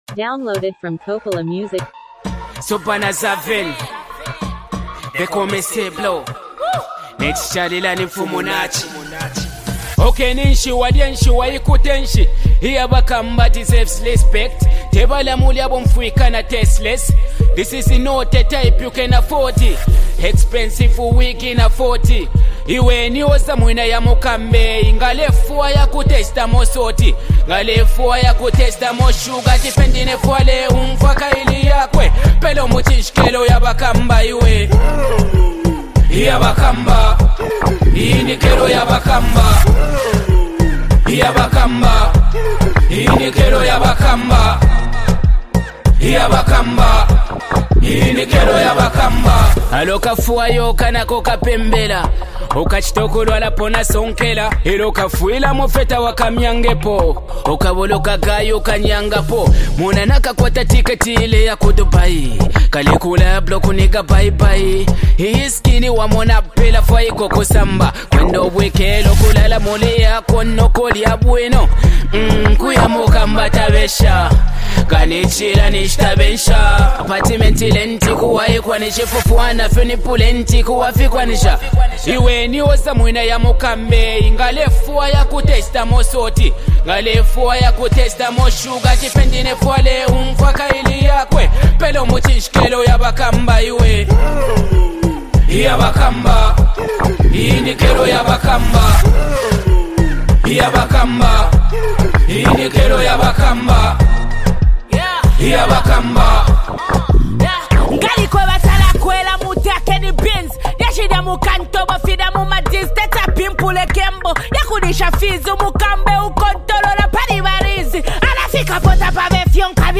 Zambian hip-hop track
Through relatable lyrics and a captivating beat